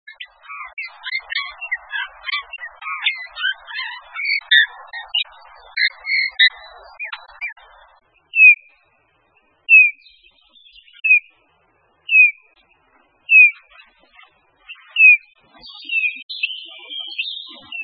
En cliquant ici vous entendrez le chant du bouvreuil pivoine.